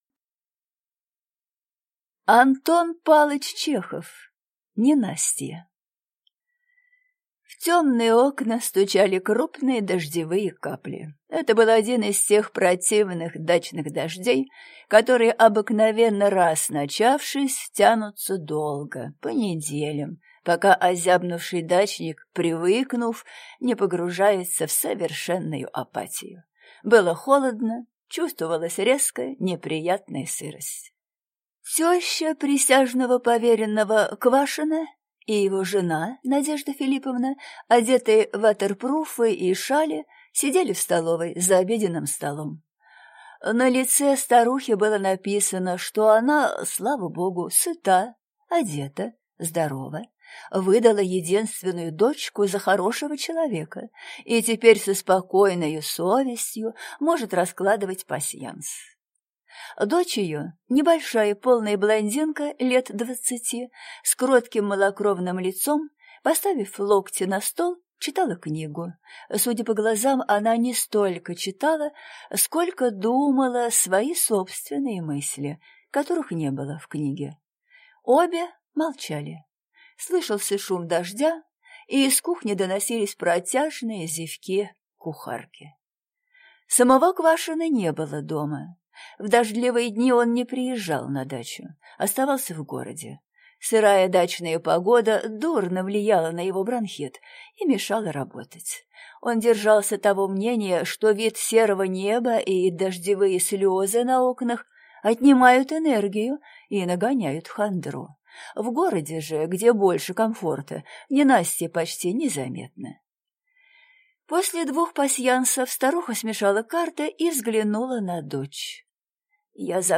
Аудиокнига Ненастье | Библиотека аудиокниг